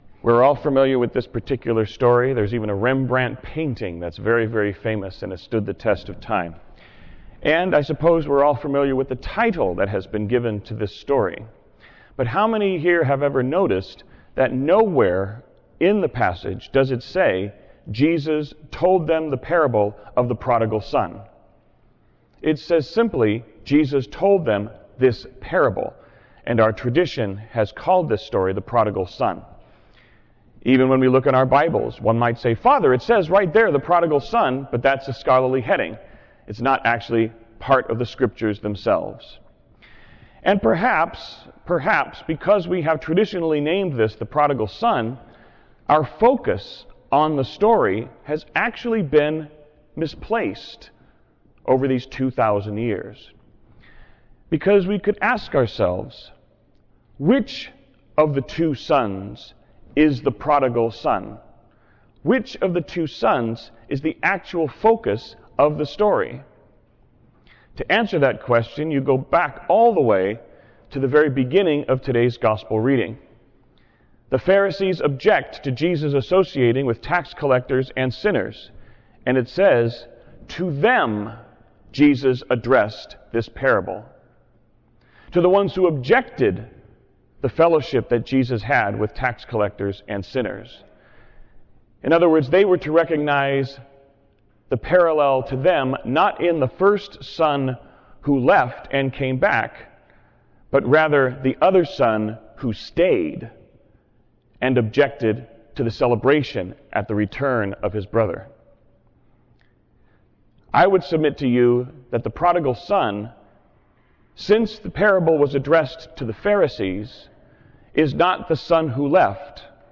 Homily (audio) – The Parable of “The Prodigal Son” 4th Sunday of Lent – Year C
Homily-4thLentCProdigalMother.wav